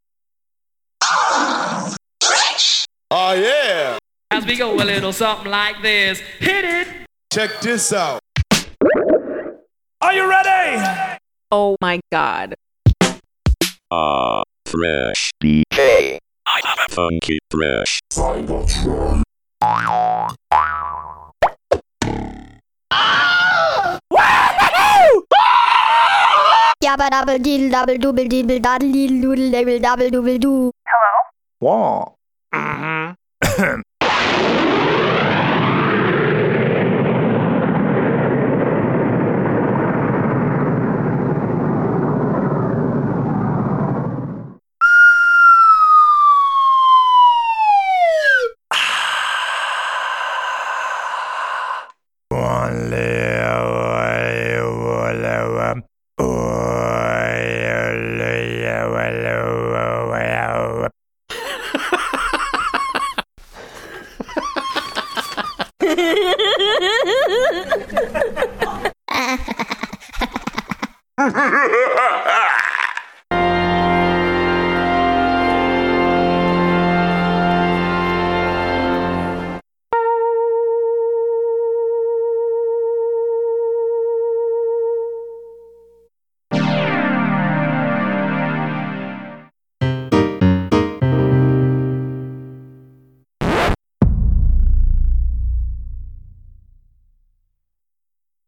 loud sound
bruh loud meme sound effect free sound royalty free Memes